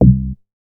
MoogAmigo D.WAV